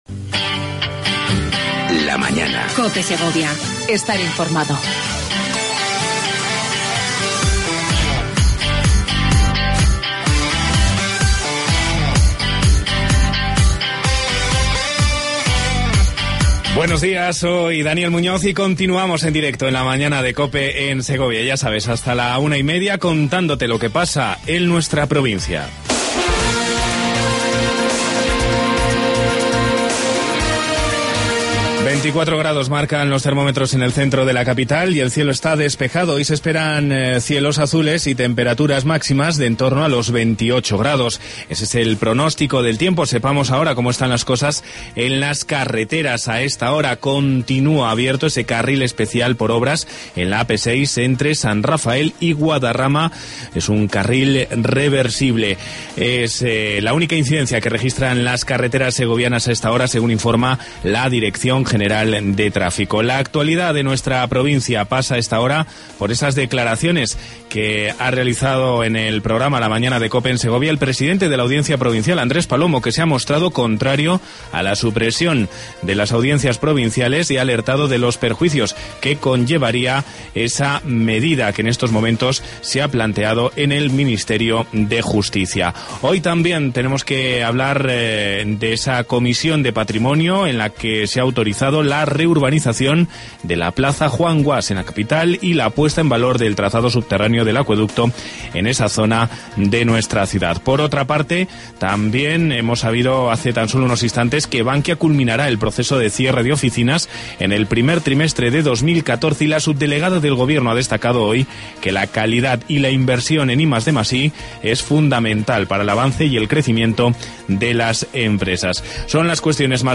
Entrevita